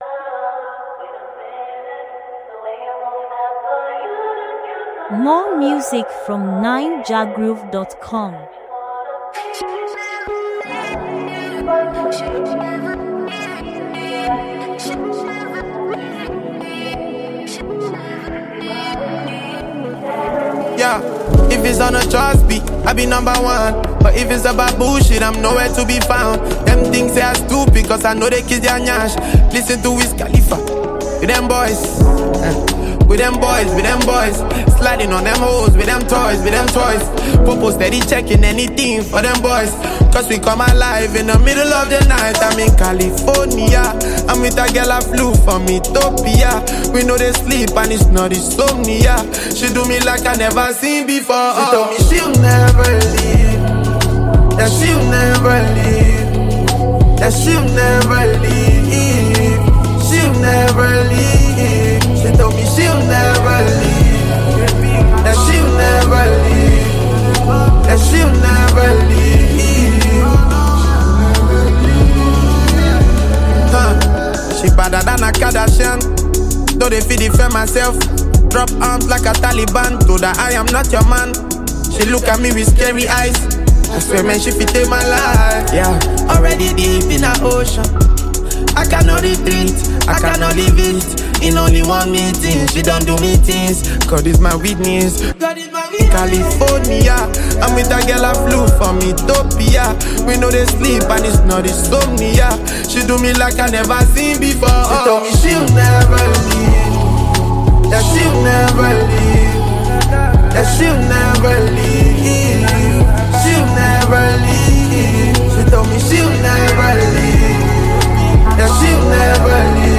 laid-back groove